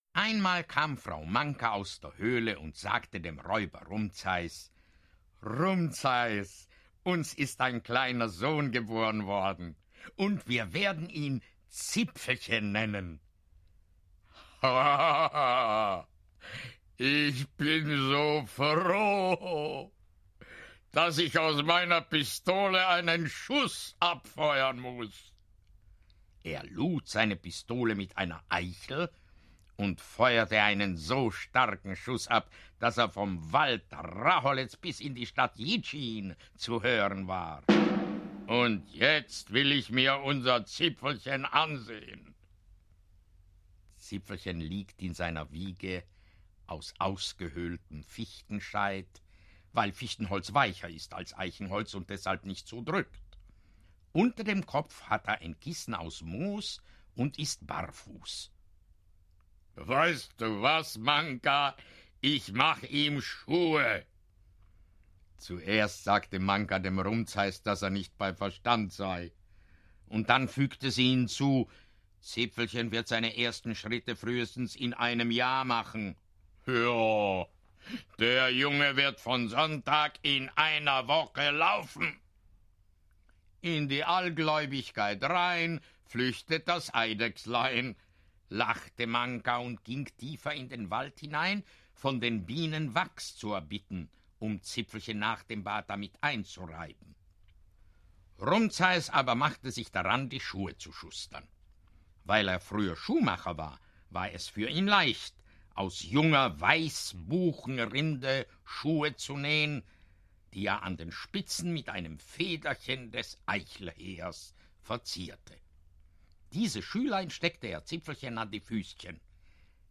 Vom raholezer Räuber Rumzais und seinem Sohne Zipfelchen audiokniha
Ukázka z knihy